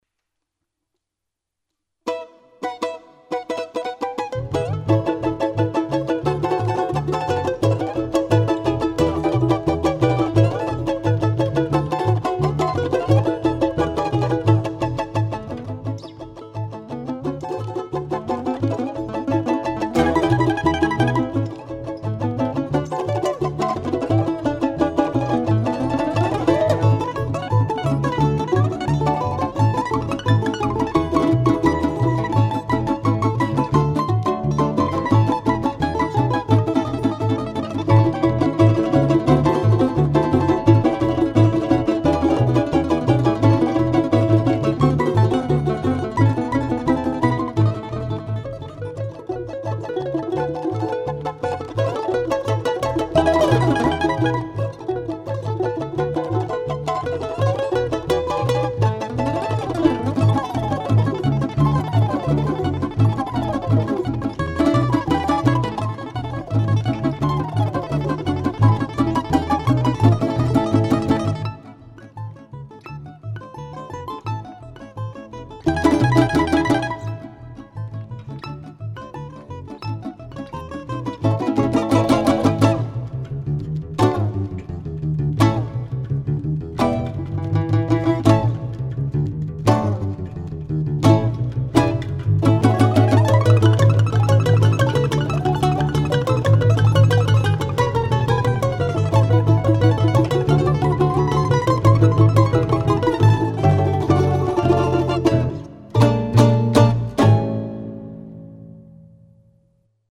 кантри (закрыта)